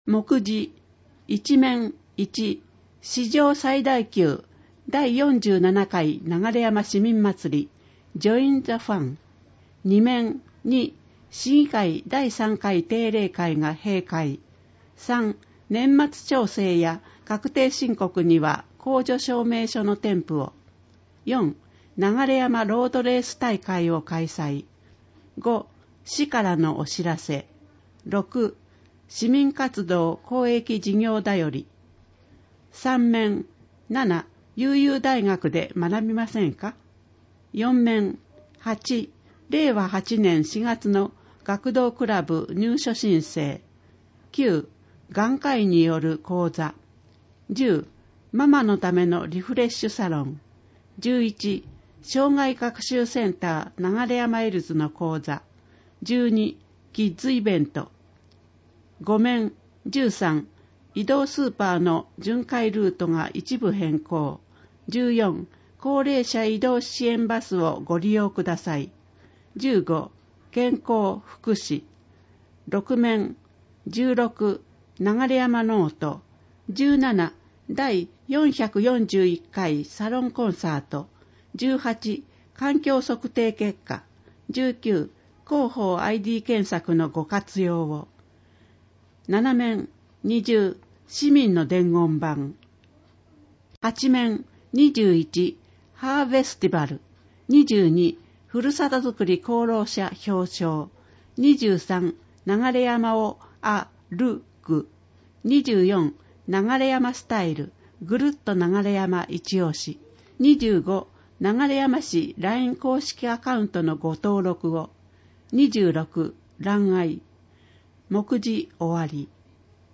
「広報ながれやま」令和7年10月21日号の内容を音声で聞くことができます。 音声データは市内のボランティア団体、音訳グループの皆さんのご協力により作成しています。